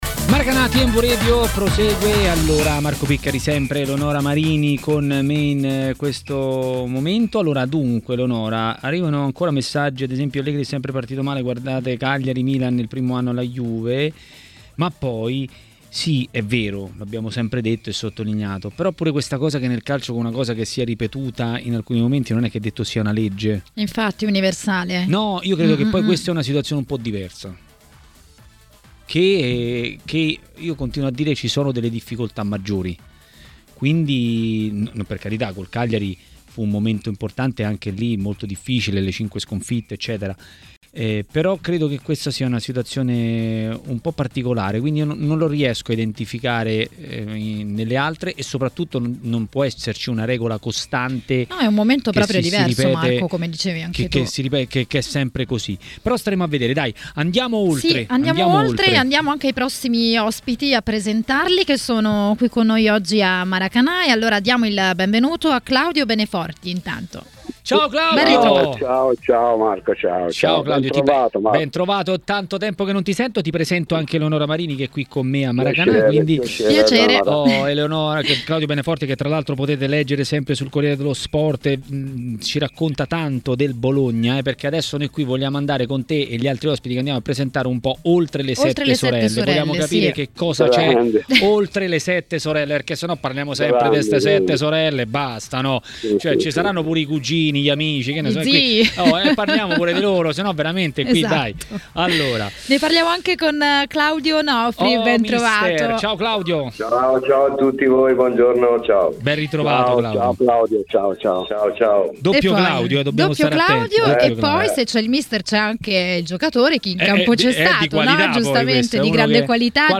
L'ex calciatore Jeda a TMW Radio, durante Maracanà, ha detto la sua sul Cagliari e sulle altre squadre sotto alle "sette sorelle" in Serie A.